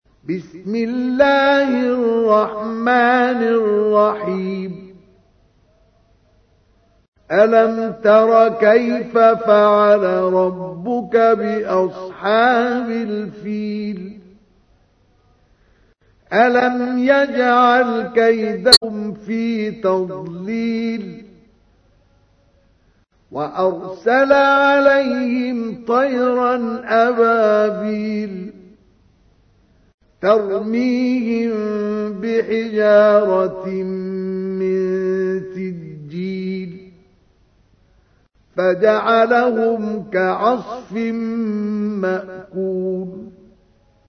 تحميل : 105. سورة الفيل / القارئ مصطفى اسماعيل / القرآن الكريم / موقع يا حسين